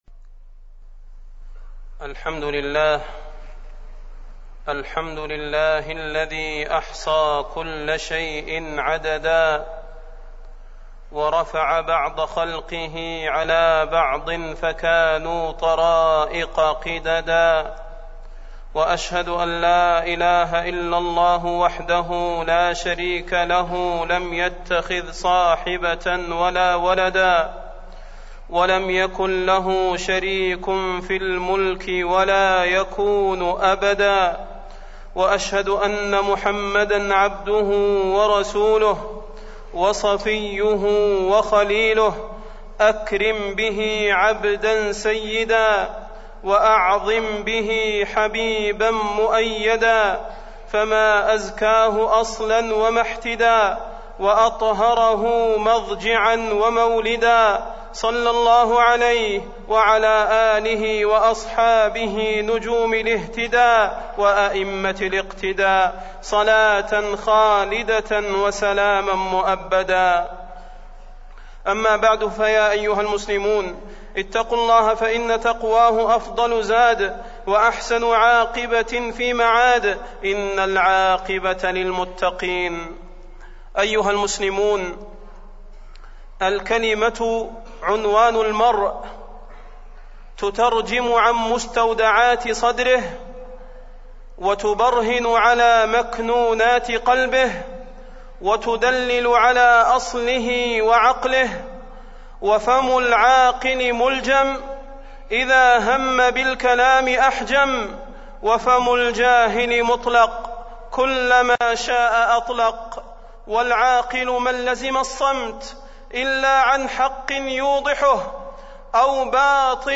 فضيلة الشيخ د. صلاح بن محمد البدير
تاريخ النشر ١٦ محرم ١٤٢٦ هـ المكان: المسجد النبوي الشيخ: فضيلة الشيخ د. صلاح بن محمد البدير فضيلة الشيخ د. صلاح بن محمد البدير آفات اللسان